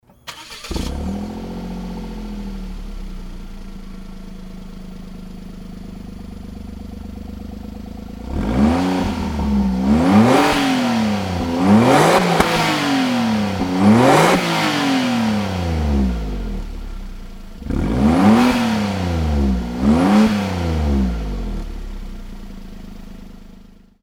排気音のサンプル
排気音は本当に静かなので
純正マフラーと言っても通用するレベルの音量ですね。
アクセルを踏み込むと遠くの方でブオーンと音が聞こえます。